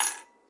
标签： Drums RimShot Drum Rim Snare
声道立体声